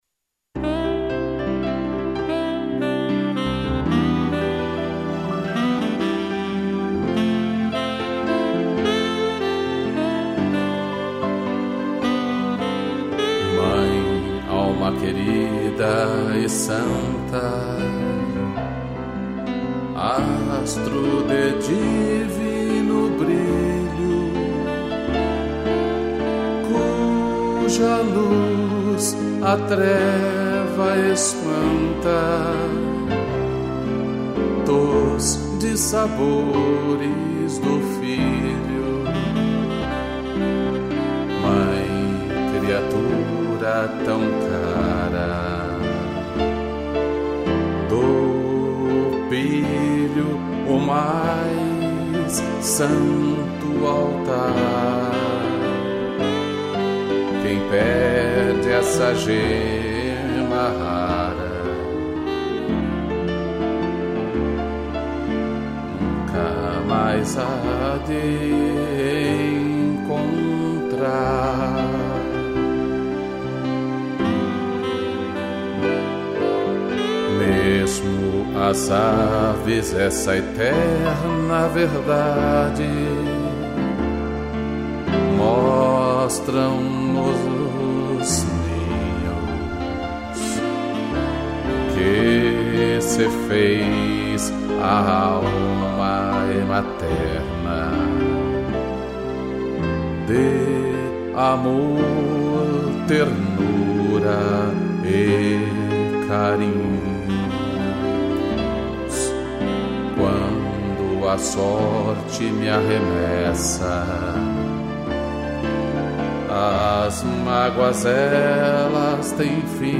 2 pianos, strings e sax